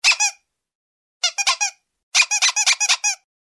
• Качество: 128, Stereo
без слов
Звук резиновой игрушки - на смс-сообщения